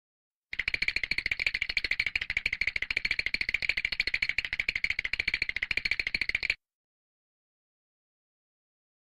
Teeth Chattering Sound sound effects free download